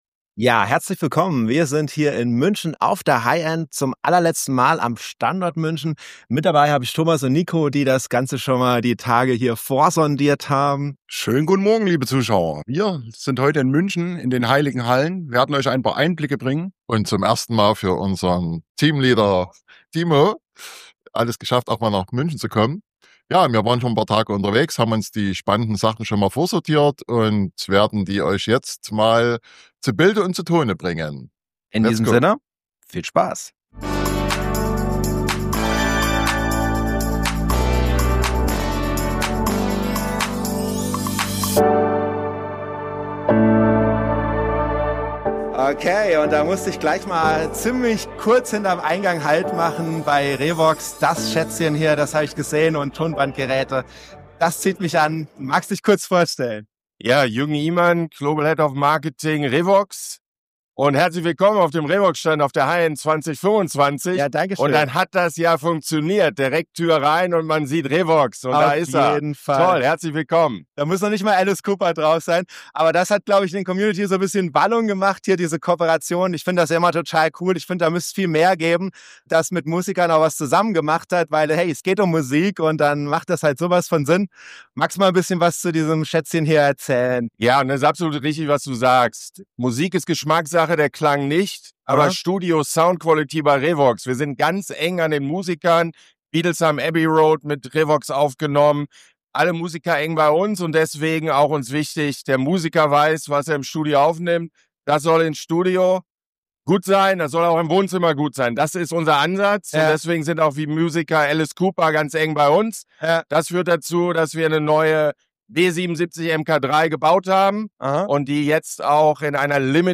Sa., 18.04.2026, ab 20.15 Uhr Wir waren zu Besuch auf der letzten HIGH END in München.
Über die Kapitelmarken kannst du dir die Interviews anschauen, die dich am meisten interessieren.